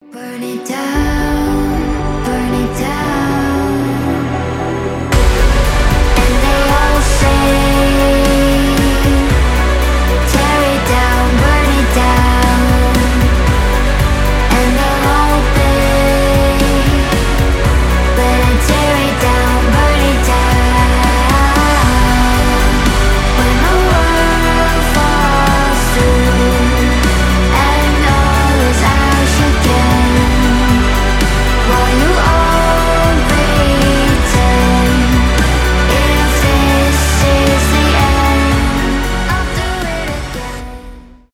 electronic rock , громкие
красивый женский голос